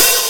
Open hat 6.wav